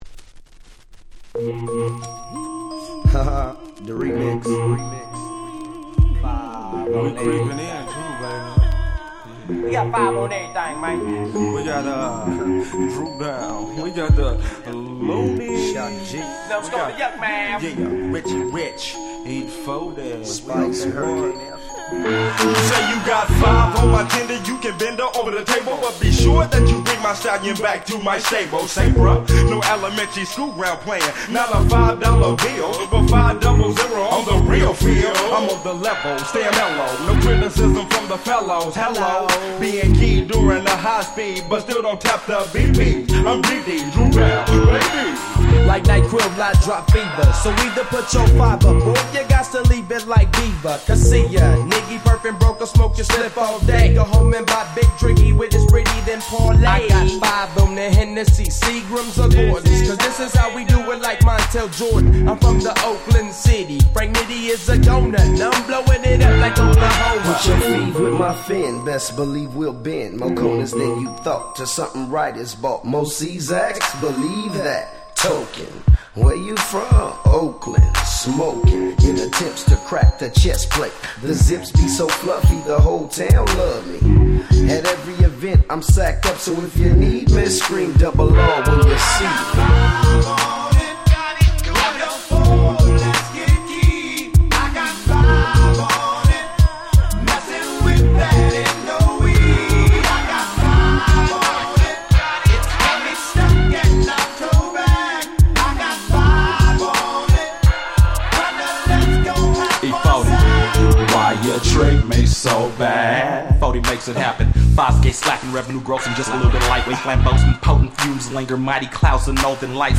95' Super Hit West Coast Hip Hop !!
Hip Hop Super Classics !!
G-Rap Gangsta Rap